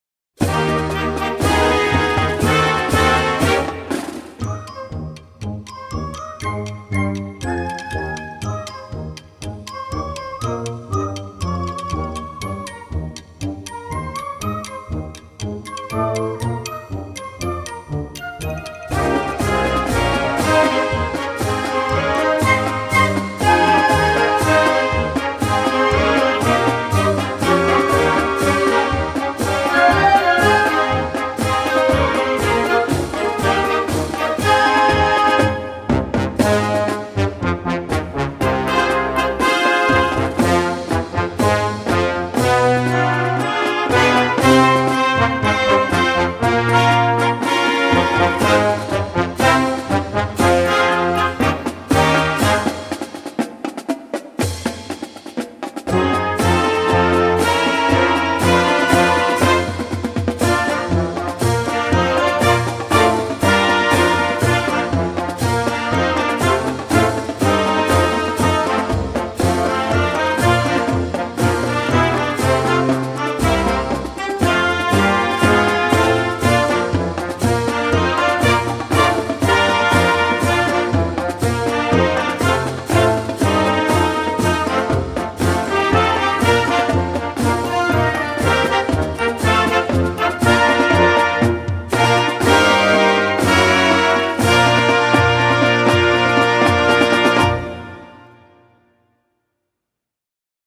Gattung: Marsch für Blasorchester
Besetzung: Blasorchester